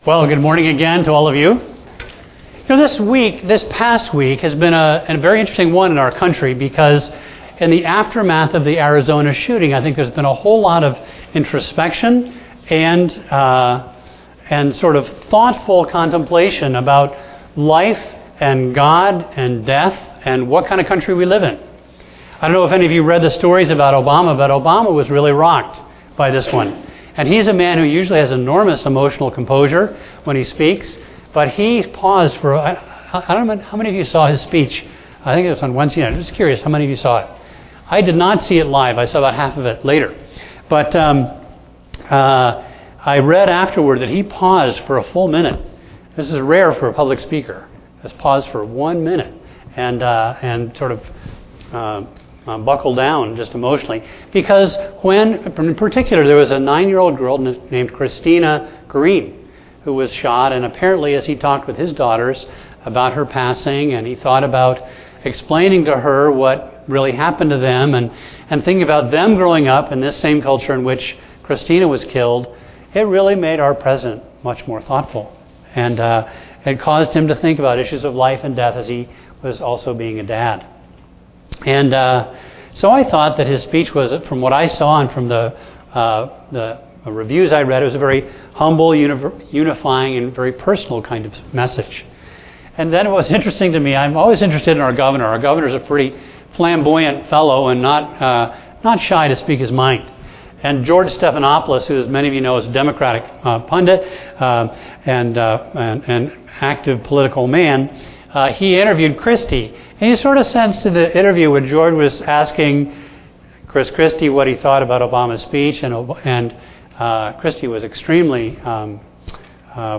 A message from the series "Elijah."